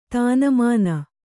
♪ tānamāna